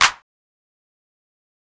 Tm8_Clap30.wav